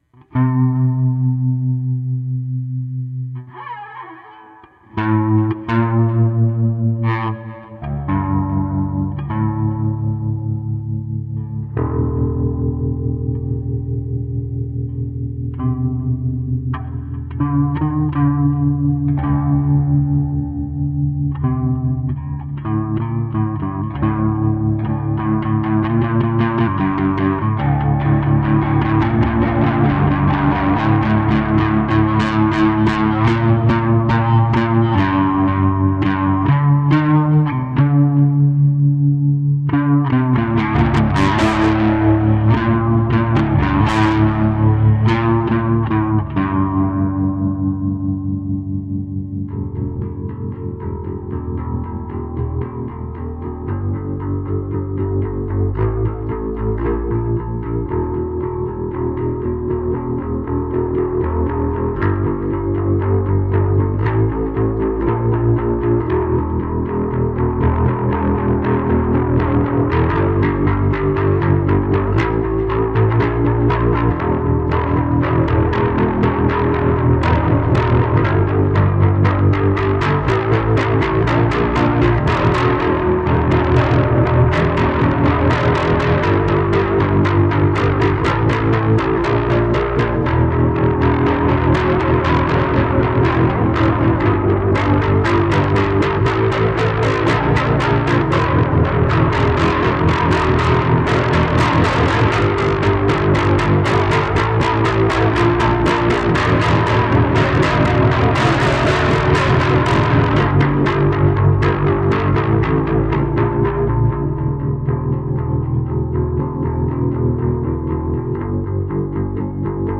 Hopefully it evokes a diablo 1 feel.